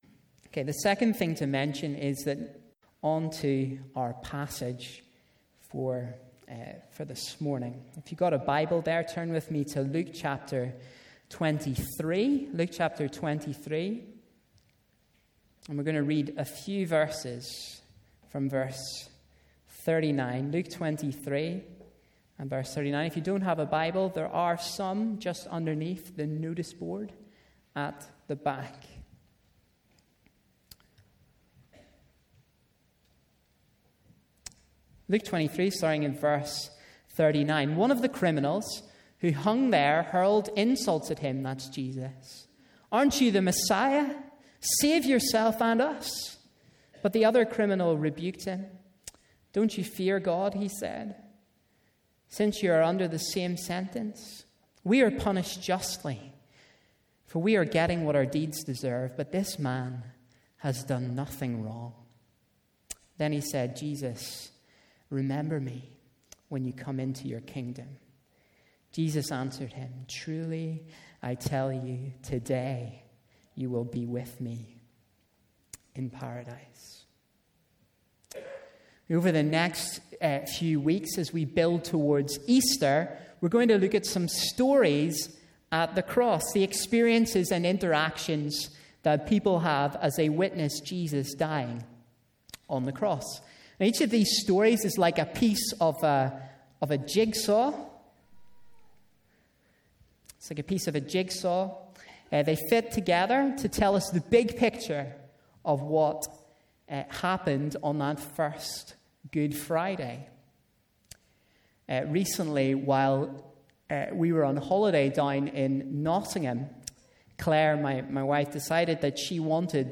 A message from the series "Stories at the Cross."